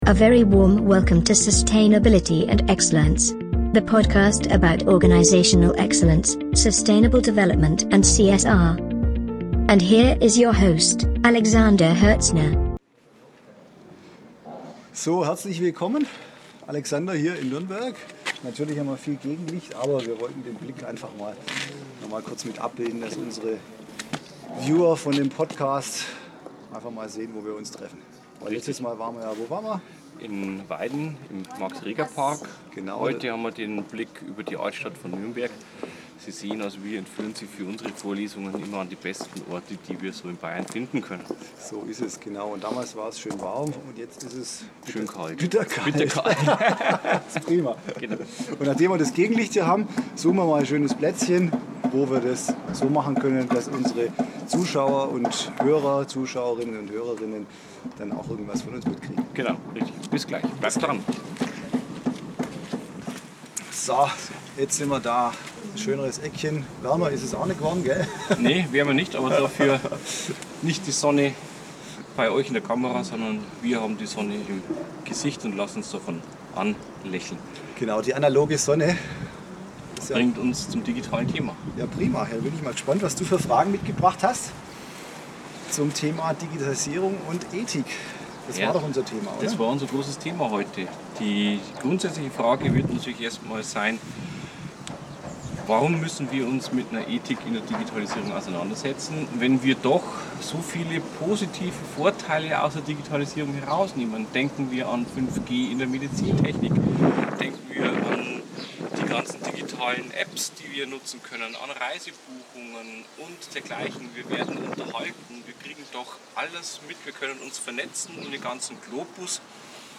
Bei Sonnenschein und Wind im Gespräch